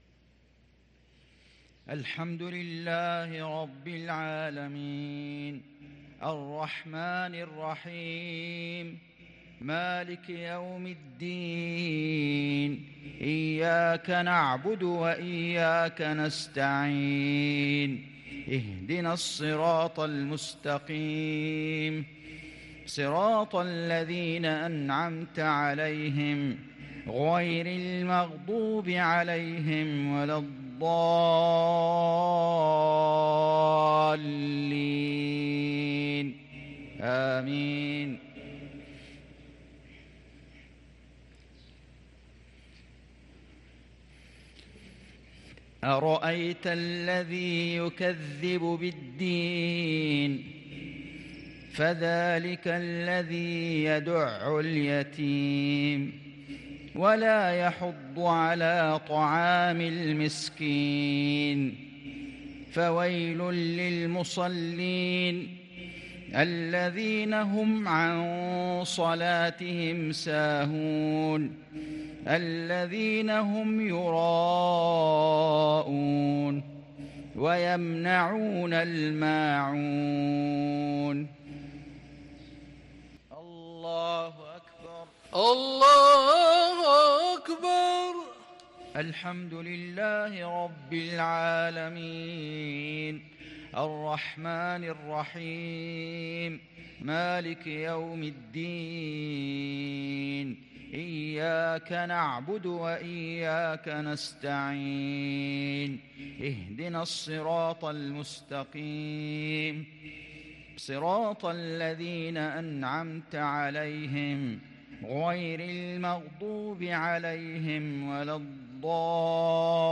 صلاة المغرب ٥ محرم ١٤٤٤هـ سورتي الماعون والنصر | Maghrib prayer from Surah al-Ma`un & an-Nasr 3-8-2022 > 1444 🕋 > الفروض - تلاوات الحرمين